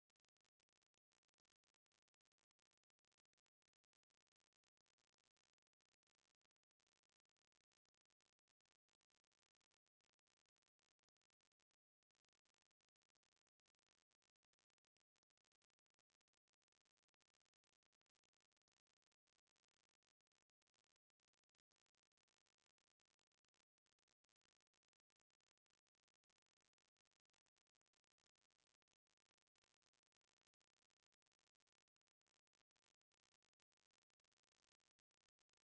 Since my sample rate is 16kHz, and data is stored in wave file format, I use ADC12 2's complement data format, left aligned.
I did what you asked, here is a file recorded with no mic and preamp input grounded:
silence.wav